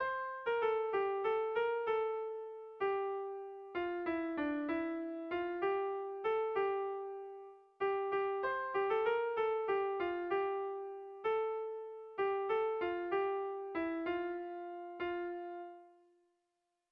Bertso melodies - View details   To know more about this section
Erromantzea
ABD